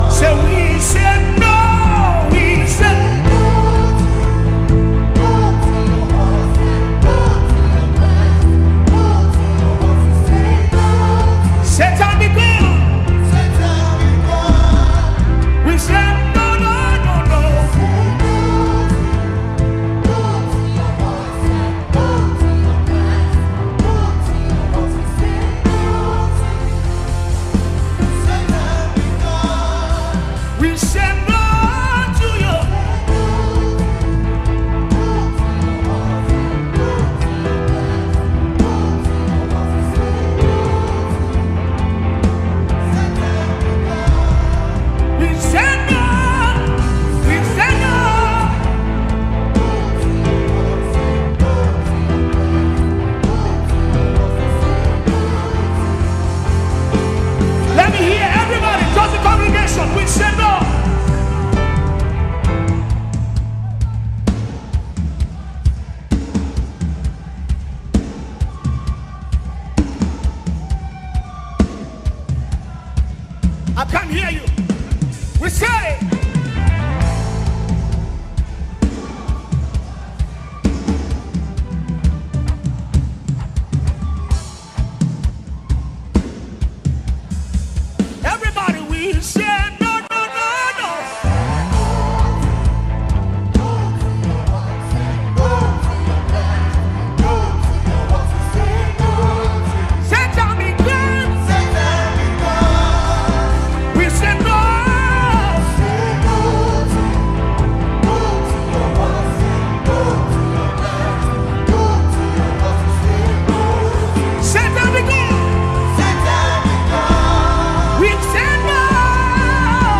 powerful worship song